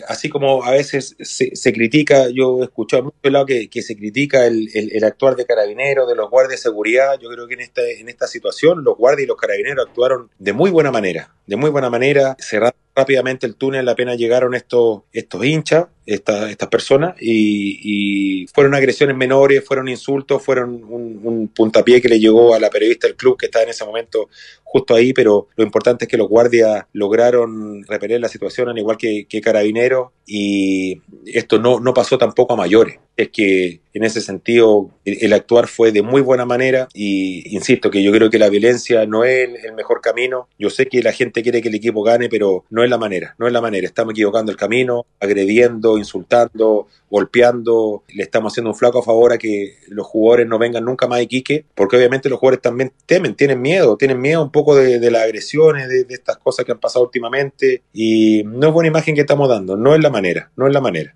Así lo detalló en diálogo con el programa Dragón Deportivo de la Radio La Nueva Super